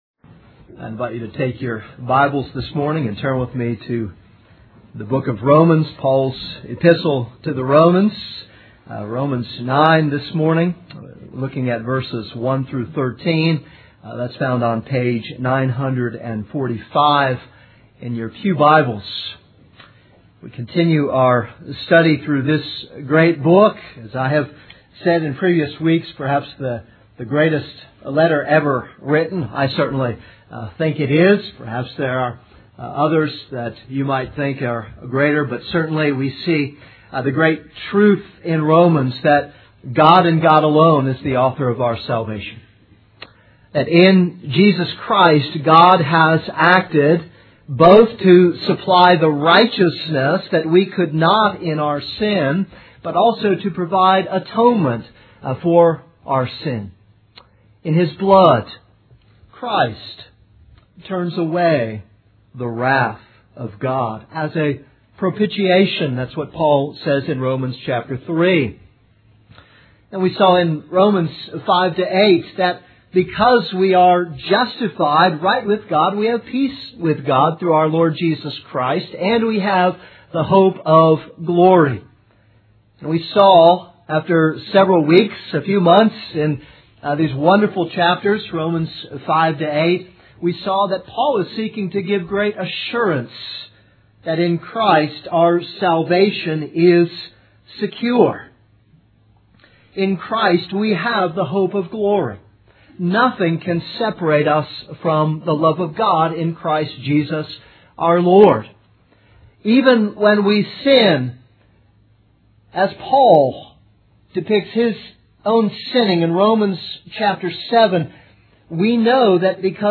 This is a sermon on Romans 9:1-13.